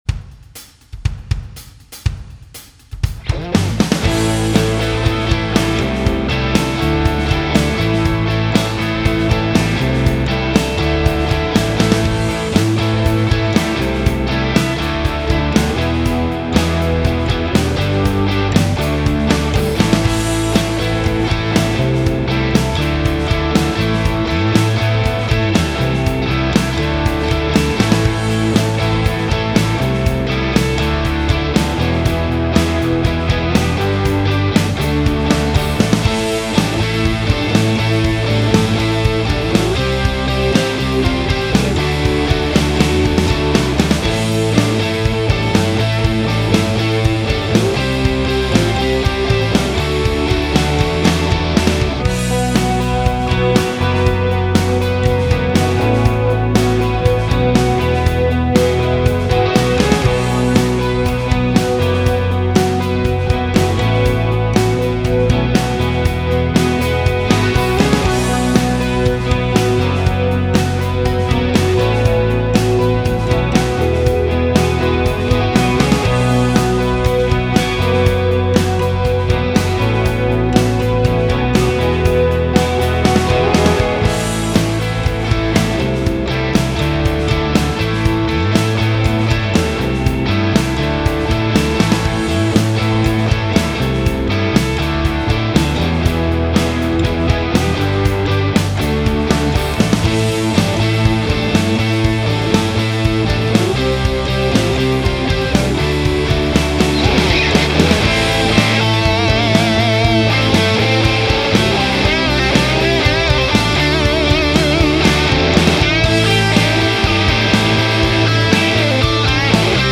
✔ Mix-Ready Tone
Amazing Rock Tones On The Go!!!
All tones were recorded with the Boss Katana Go.
No Post Processing.
The Katana Go went straight into a Focusrite 2i2 Audio Interface from the Headphone Mini Jack.